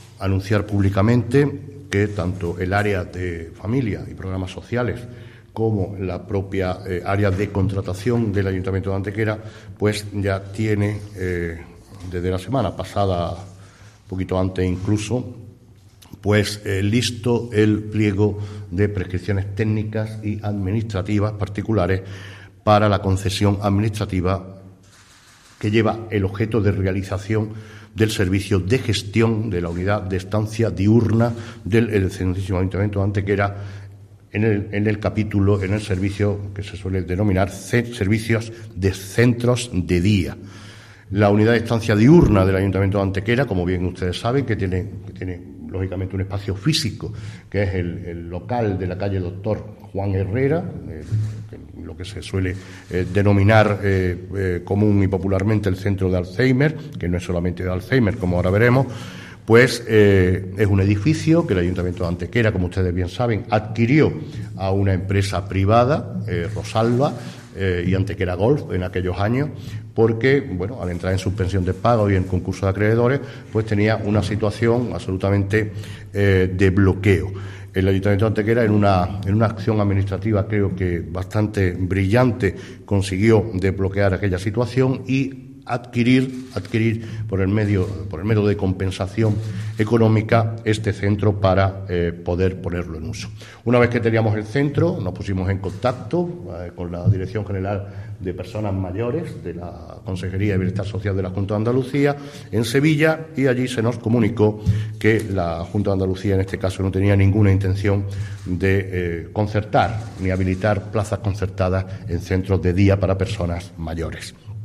Esta instalación tendrá cobertura para 80 plazas y una prestación de servicio mínima entre las 09:00 y las 18:00 horas. El alcalde de Antequera, Manolo Barón, ha confirmado hoy en rueda de prensa que ya se ha concluido la redacción de los pliegos correspondientes que regirán el concurso público para la concesión administrativa de la gestión de la Unidad de Estancia Diurna del Ayuntamiento de Antequera, nuevo servicio que se ubicará en el edificio recuperado por el Consistorio y que iba a ser destinado a su explotación como Centro de Día de Alzheimer por parte de la hoy extinta empresa Rosalba.